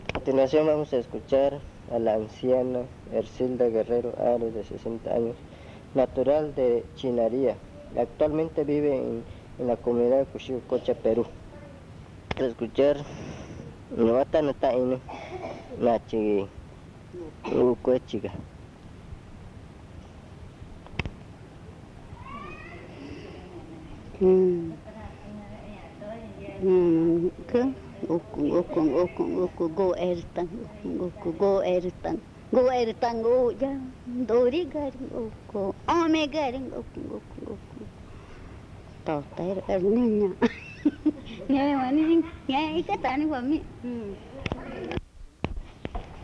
Canción sobre la coronilla de la muchacha
Pozo Redondo, Amazonas (Colombia)